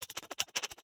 rabbit-v3.ogg